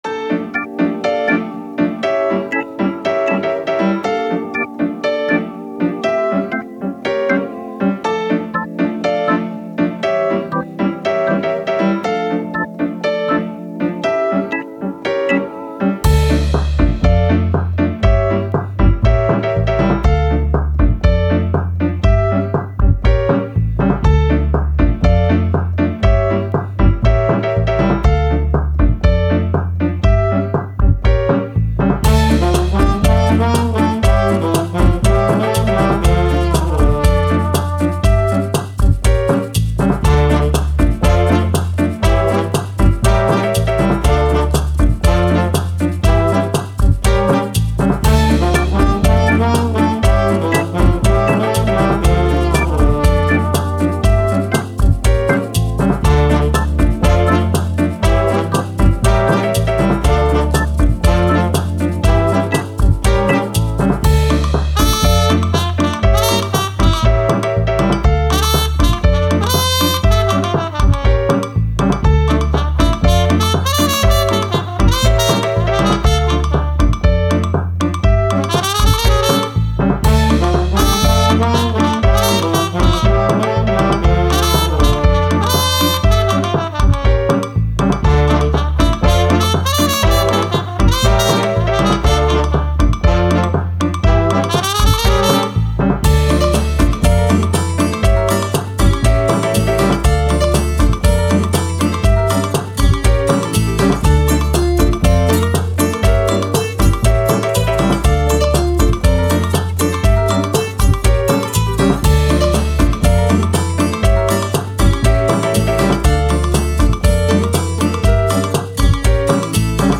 Jazz, Salsa, Latin, Playful, Upbeat